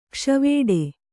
♪ kṣvēḍe